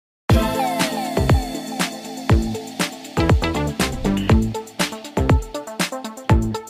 Dance Samsung Ringtone Bouton sonore